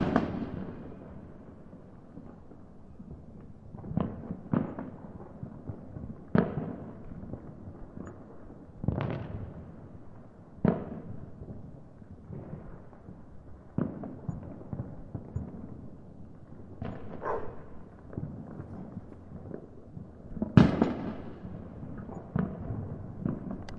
新年鞭炮 3
描述：新年鞭炮，氛围记录，第一分钟01/01/2019 MX
Tag: 爆炸 烟火 烟花 爆竹 新年